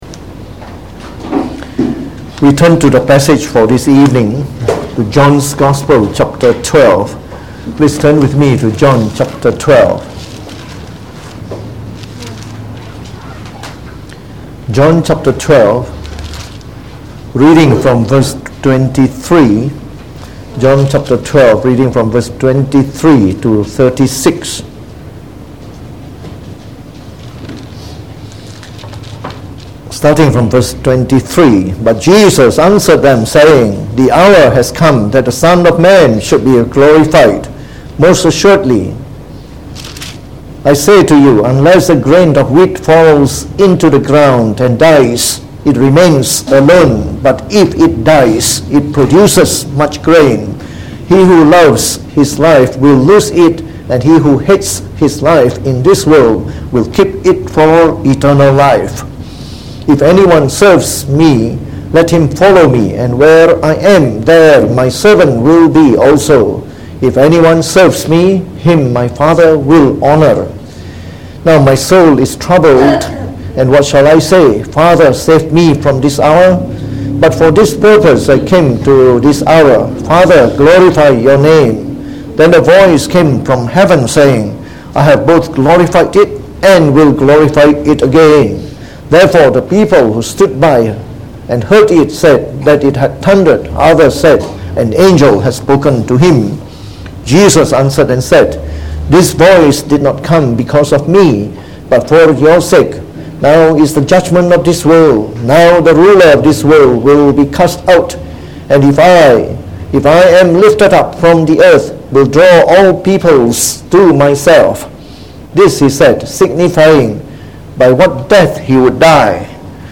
Preached on the 10th March 2019.